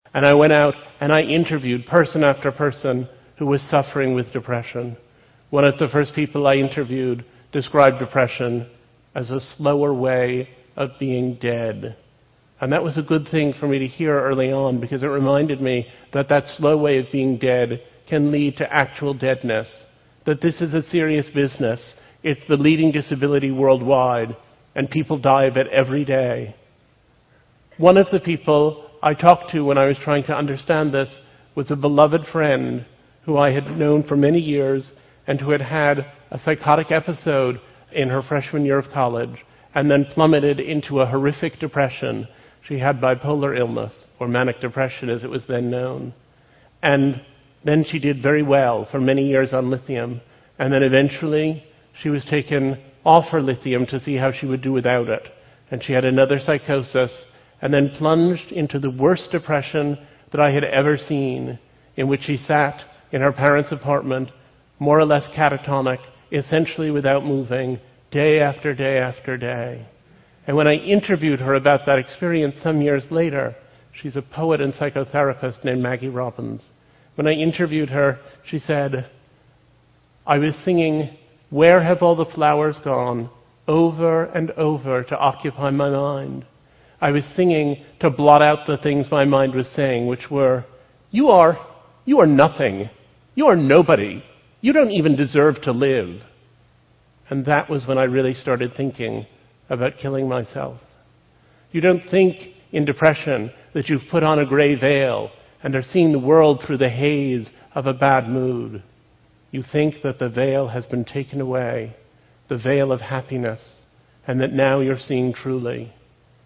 TED演讲:抑郁,我们各自隐藏的秘密(4) 听力文件下载—在线英语听力室